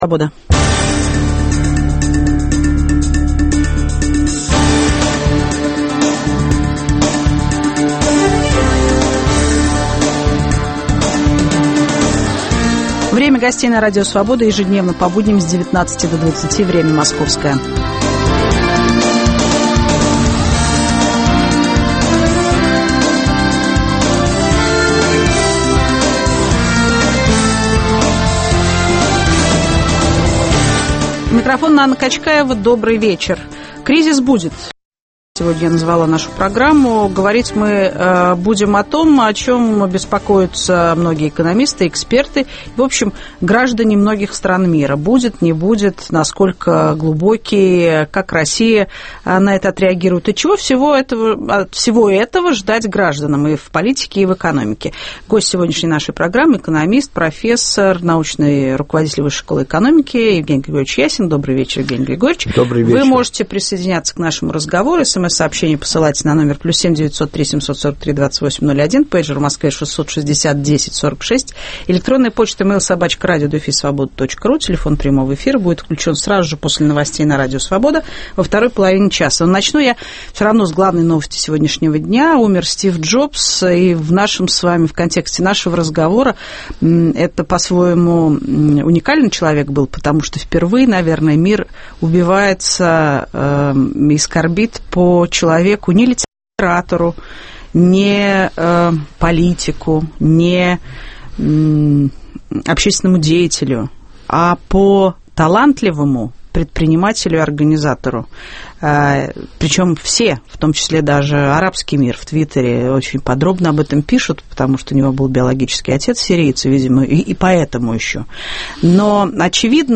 Как он отразится на экономической и политической ситуации в России, чего ждать гражданам? В студии - экономист, профессор Высшей школы экономики Евгений Ясин.